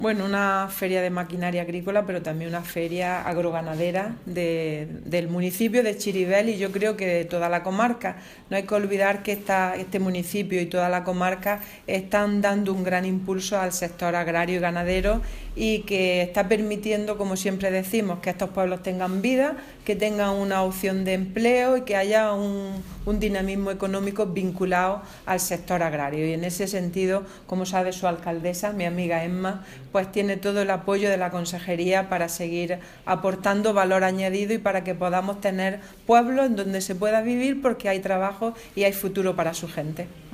La consejera de Agricultura ha inaugurado en Chirivel la IX Feria Comarcal de Maquinaria y Productos Agrícolas y Ecológicos 'Terracultura 2017
Declaraciones consejera 'Terracultura'